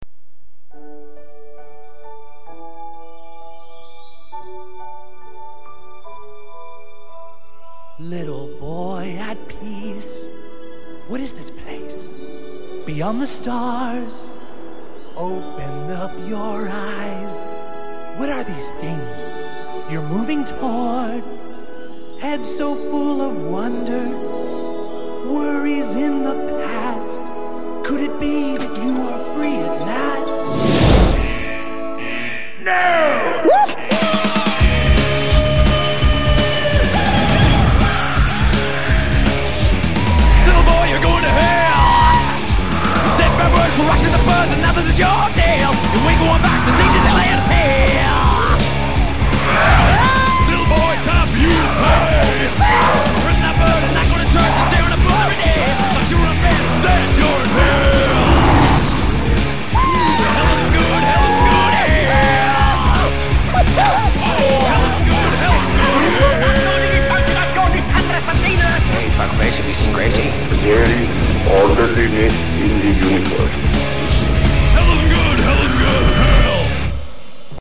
вырезана из фильма